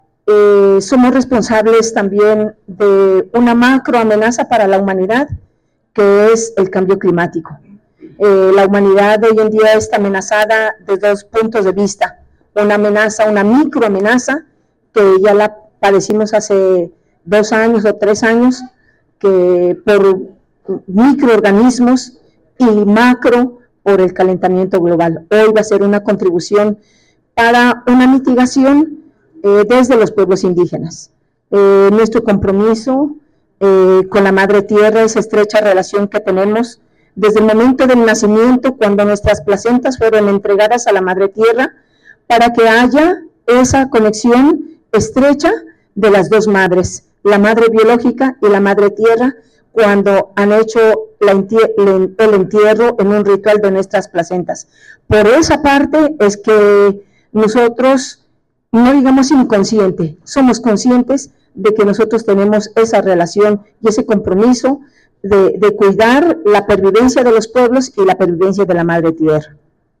En el corazón del diálogo interinstitucional entre la Universidad de Nariño y la Universidad de Caldas, se entreteje una narrativa profunda de responsabilidad cultural, histórica y educativa que se hizo visible en el IV Coloquio de Sociolingüística e Interculturalidad, realizado en la sala Carlos Nader.